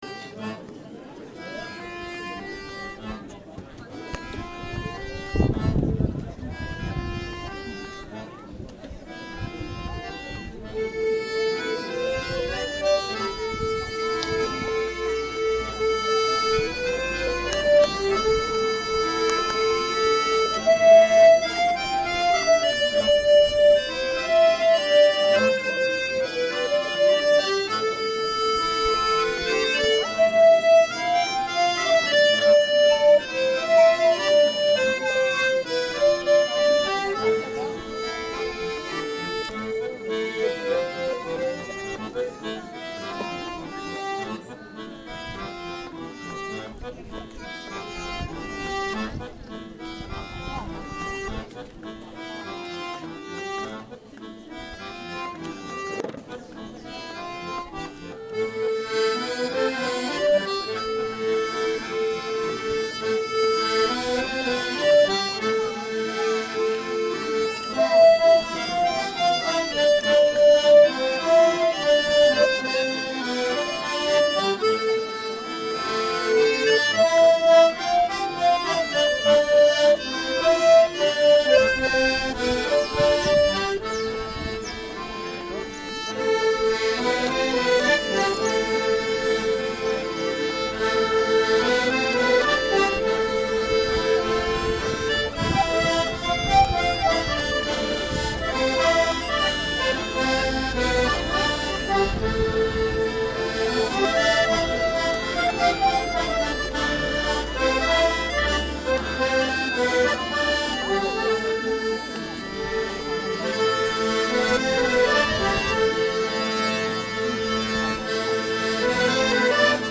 04_gavotte-accordeons.mp3